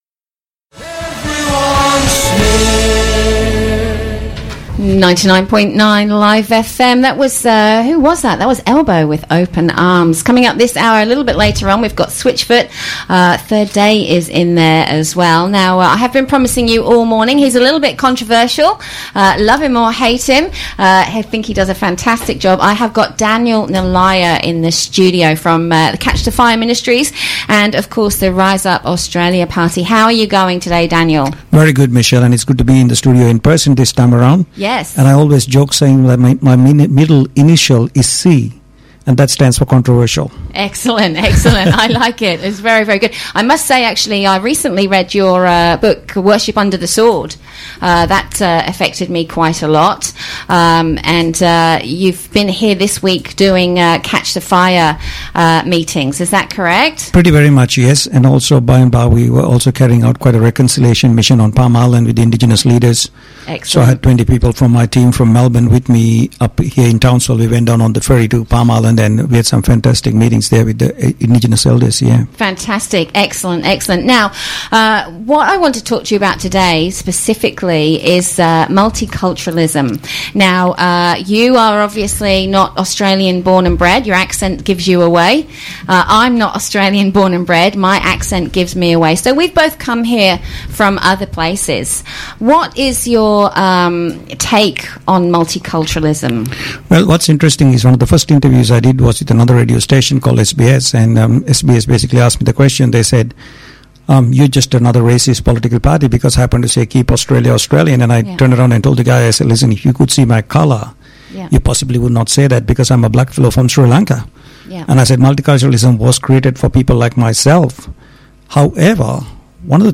Townsville Radio Interview Link
Townsville-Interview.mp3